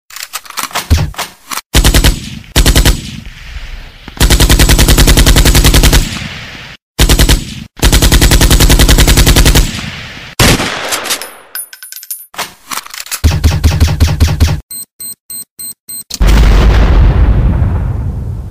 weapons-sounds_25388.mp3